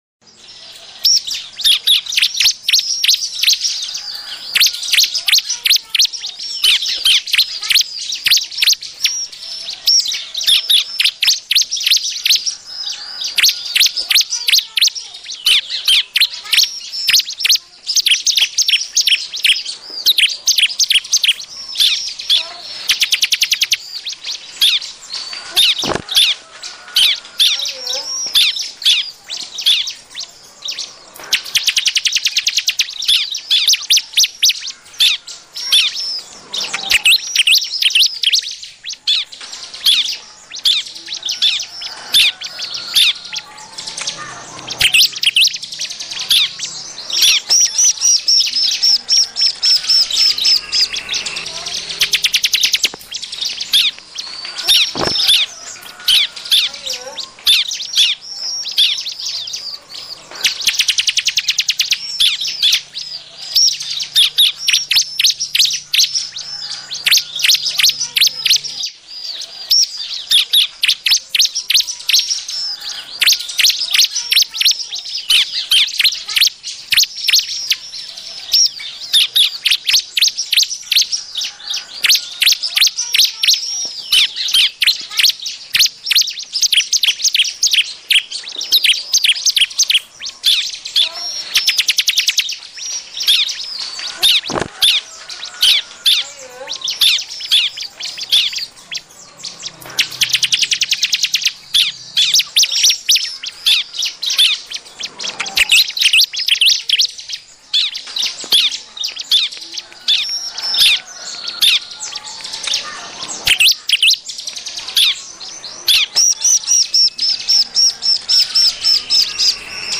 Suara Burung Ciblek Liar Ribut
Kategori: Suara burung
suara-burung-ciblek-liar-ribut-id-www_tiengdong_com.mp3